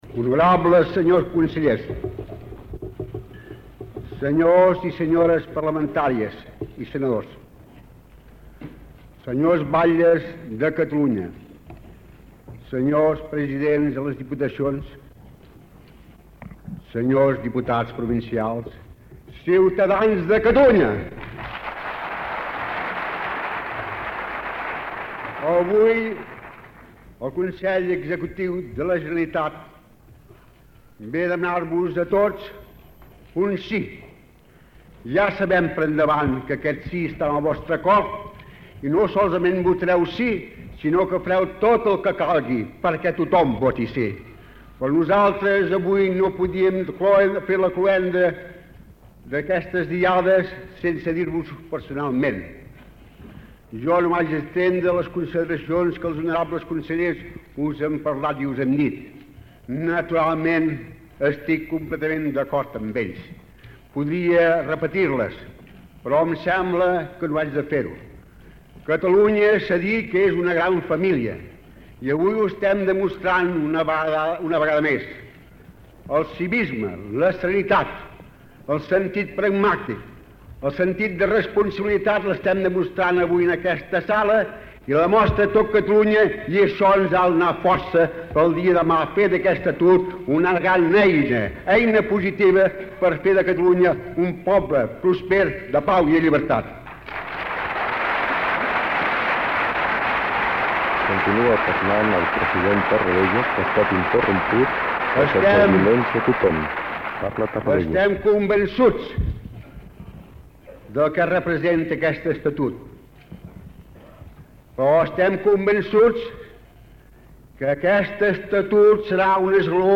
Transmissió del discurs del president de la Generalitat Josep Tarradellas, al Palau de Congressos de Monjuïc, davant la totalitat dels representants de Catalunya elegits democràticament en l'acte de cloenda de la campanya institucional del referèndum de l'Estatut de Catalunya.
Informatiu